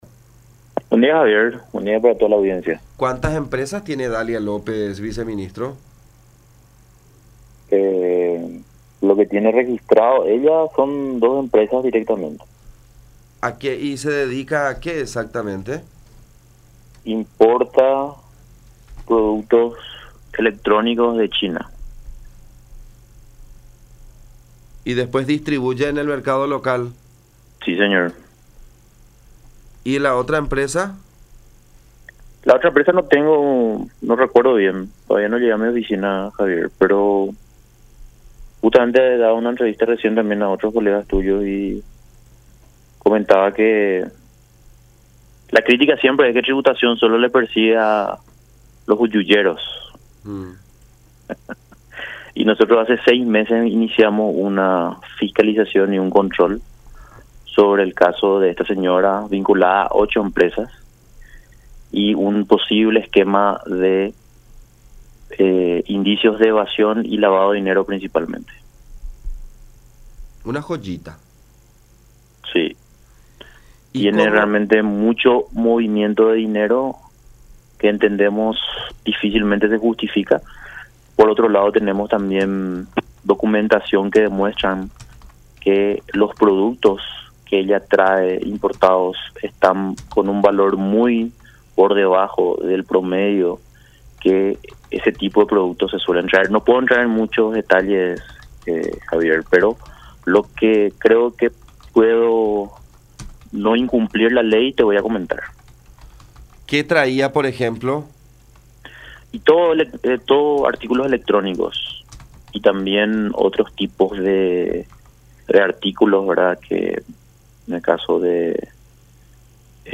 “La mayoría de las empresas de frontera adquirían de su empresa, porque los precios eran muy bajos”, reveló el viceministro en diálogo con La Unión, por lo cual afirmó que enviaron informes al respecto a la Secretaría de Prevención de Lavado de Dinero (SEPRELAD) en diciembre pasado.